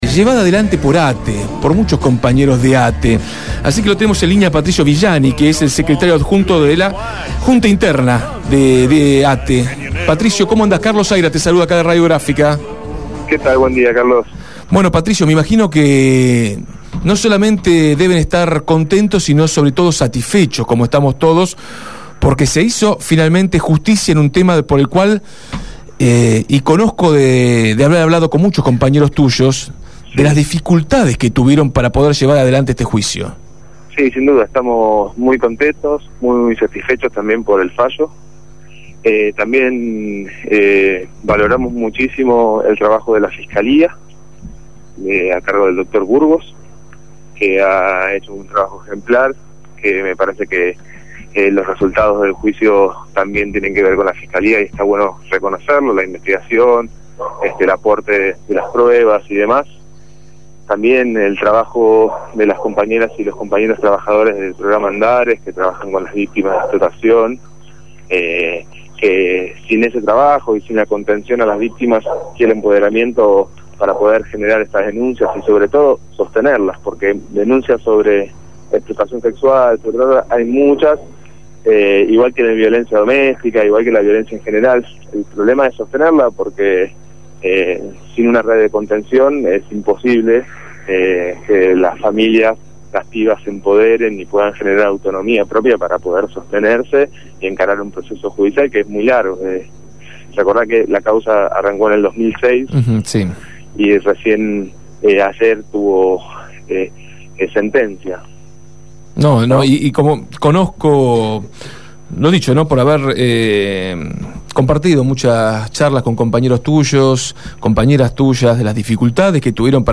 conversó en Desde el Barrio acerca del fallo en el Juicio sobre los casos de proxenetismo y explotación sexual infantil en el barrio de la Boca.